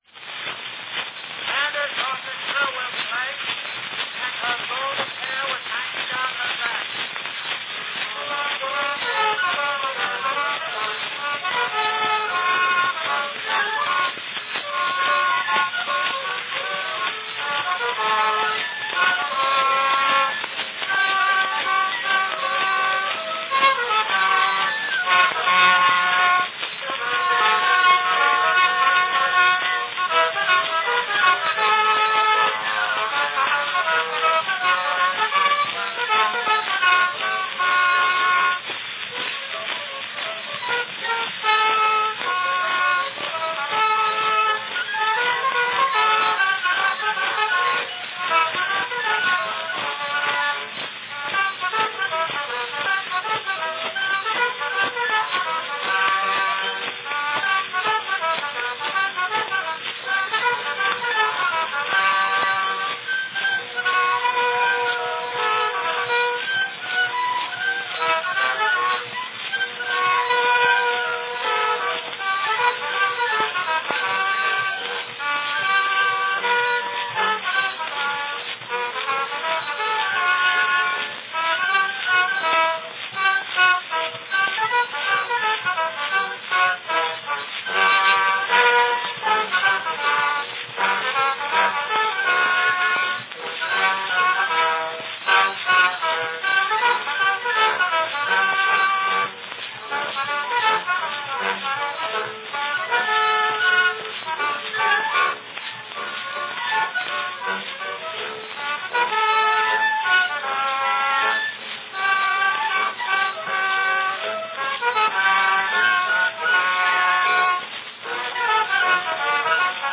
Company Chicago Talking Machine Company
Category Orchestra
As was typical in recording sessions of this period, multiple phonographs were used simultaneously in order to make several copies of the performance at a time.   From the sound of this recording, it seems the phonograph used to cut this particular record was a tad too close to the cornetist